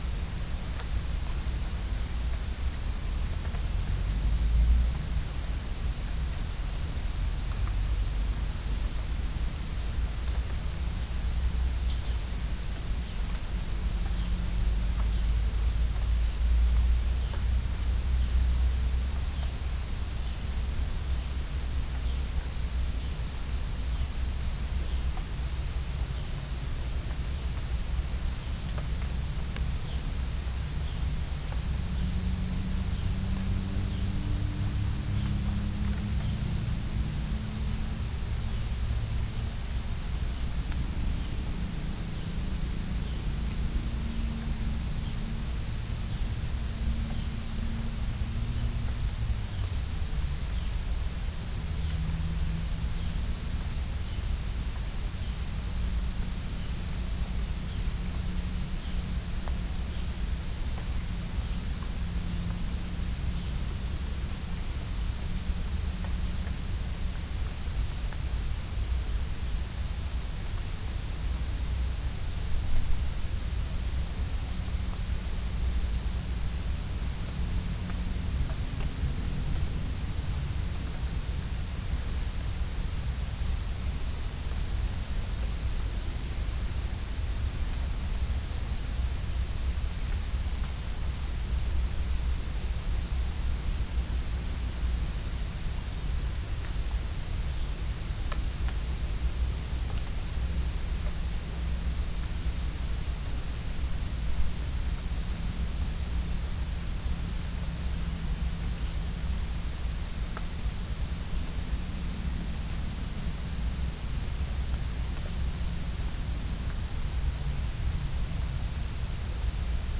Pycnonotus goiavier
Chalcophaps indica
Pycnonotus aurigaster
Halcyon smyrnensis
Orthotomus ruficeps
Todiramphus chloris
Dicaeum trigonostigma